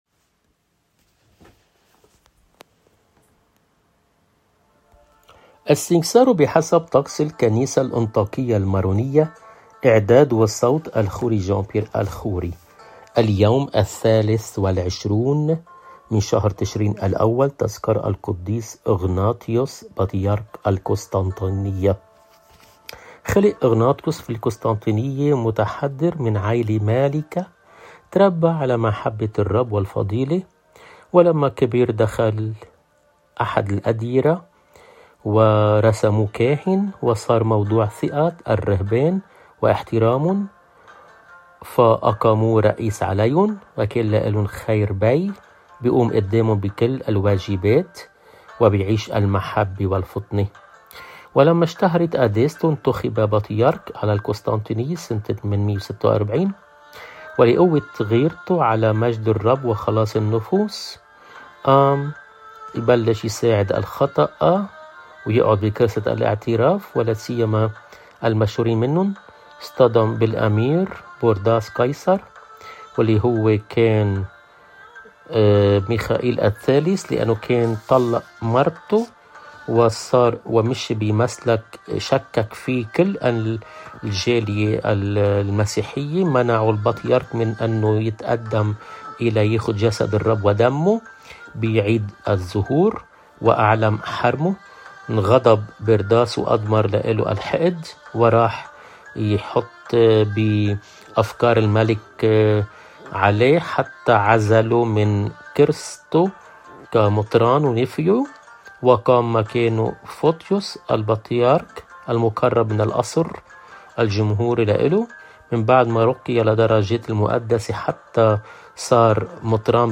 القديس أغناطيوس بطريرك القسطنطينية «قدّيس اليوم» | khoddam El Rab